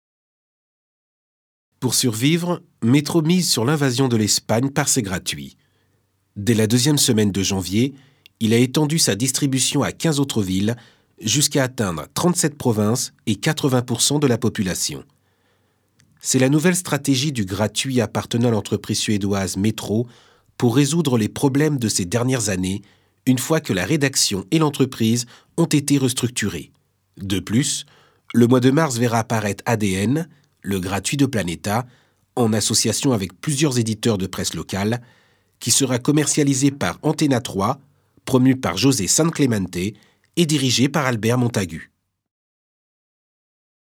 Couleur de la voix : Voix chaude et profonde pour narration ; posée, grave et naturelle pour documentaire, institutionnel, e-learning, corporate, voice over, audioguide ; cool, dynamique, sexy ou joué pour pub.
Sprecher französisch für Hörbücher, Dokumentation e-learning Werbung -
Sprechprobe: eLearning (Muttersprache):
french voice over artist: documentaire, institutionnel, e-learning, corporate, voice over, audioguide